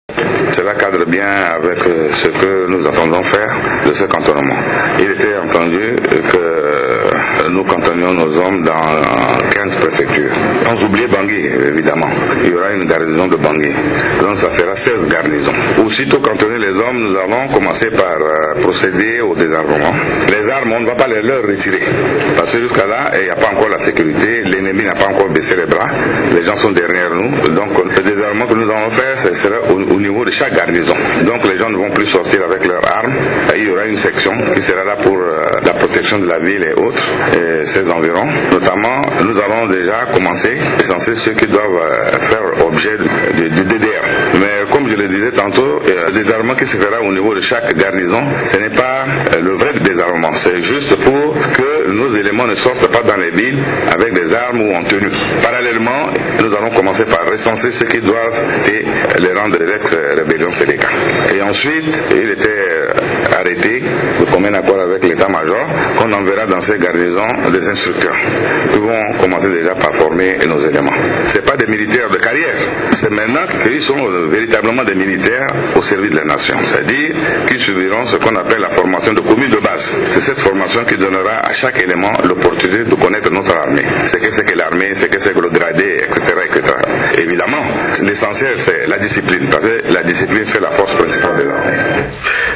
Suivre les propos du chef de l’Etat (écouter ou télécharger le son)